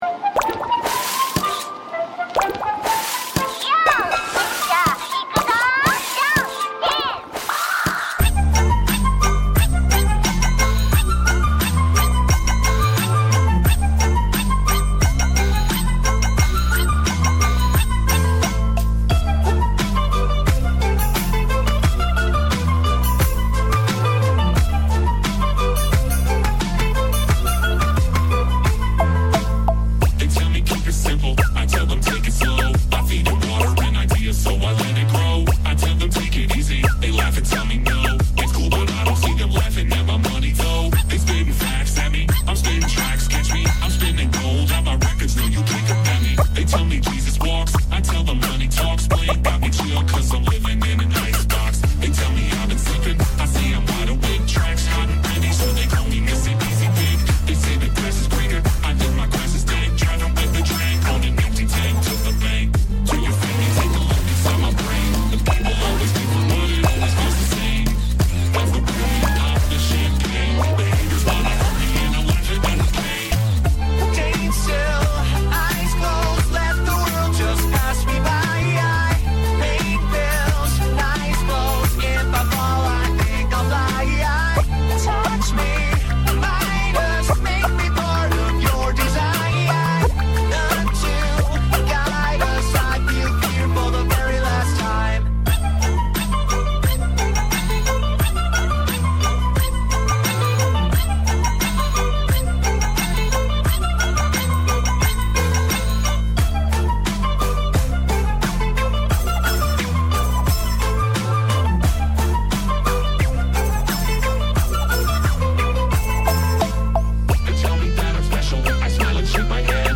BPM60-88
Audio QualityPerfect (High Quality)
Full Length Song (not arcade length cut)